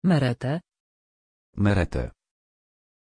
Aussprache von Merete
pronunciation-merete-pl.mp3